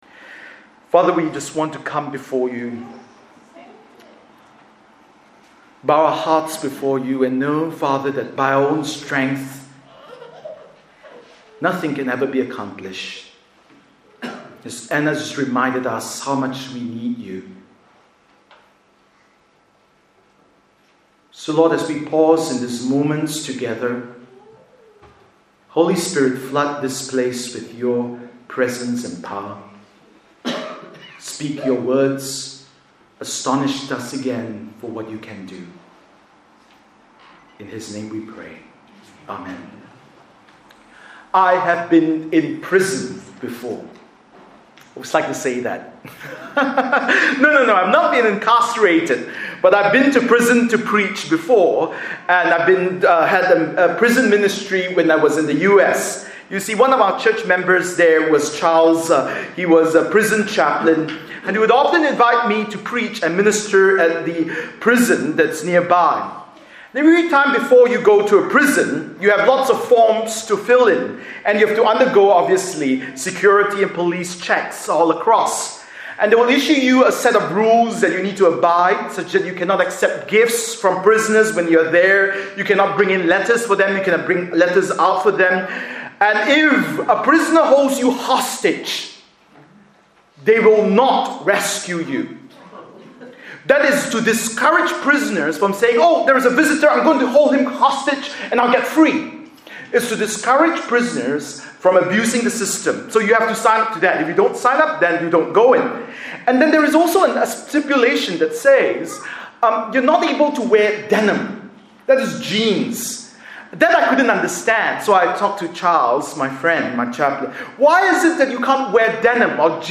Bible Text: Luke 19:28-44 | Preacher